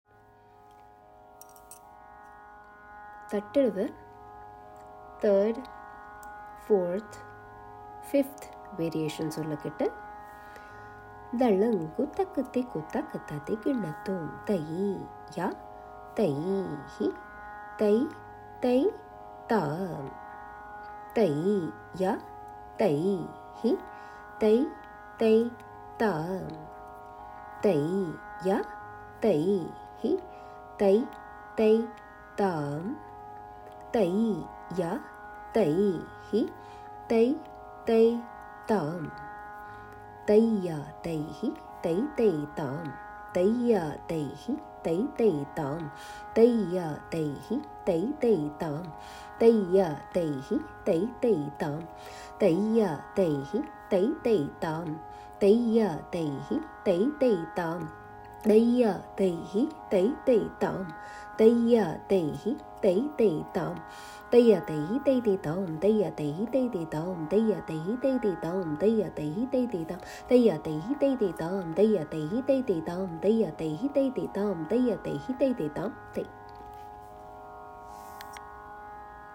Here is the Sollukettu for the Third, Fourth and the Fifth step of Tatta Adavu in Bharatanatyam. The Bols are “Tai Ya Tai Hi Tai Tai Tam“.